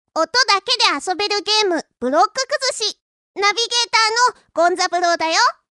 ナビゲータ・権三郎による音声ナビゲートで、音だけの世界でブロック崩しにチャレンジしてみよう！